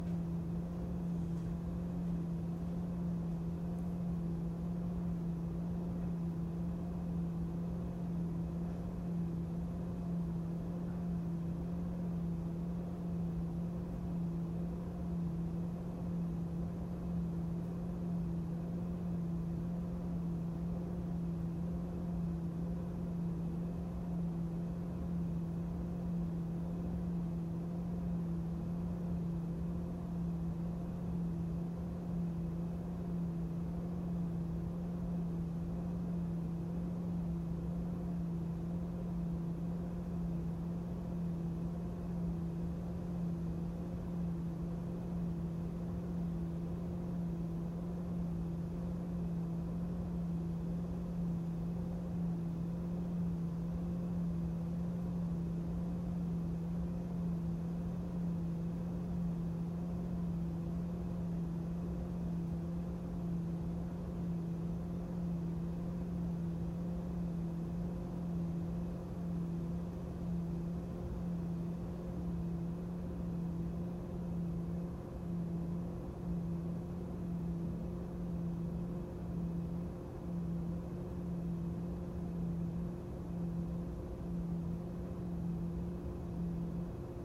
Bruit clim Mitsubishi MSZ-AP15VG
Depuis son installation en 2021, je déplore un bruit très entêtant mais surtout pas régulier (enregistrement en fichier joint).
Je précise tout de même que les unités intérieures sont toujours en mode "SILENCE"...
J'avais également pensé à un problème de régulation car au cours du temps le bruit évolue (de constant à alternatif en passant par quasi inaudible) et c'est très déroutant et gênant dans une chambre d'autant que j'habite dans une région relativement chaude (Var).
bruit-mode-silence.mp3